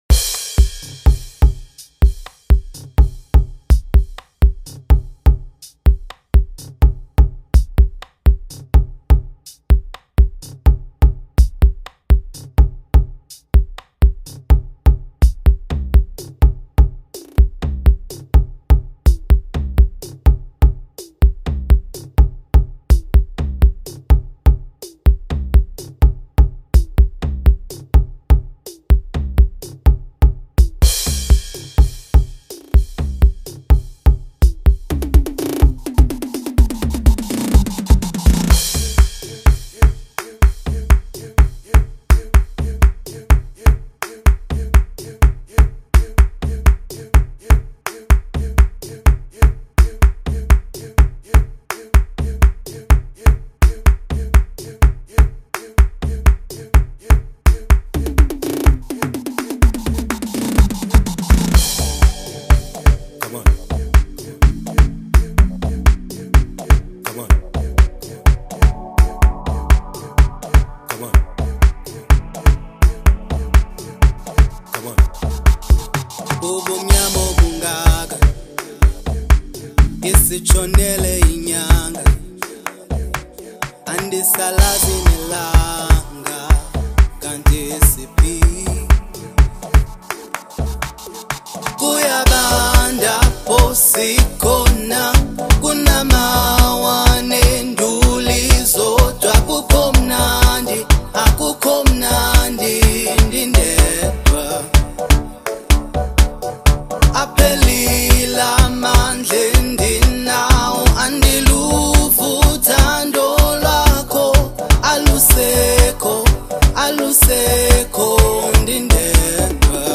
Gqom song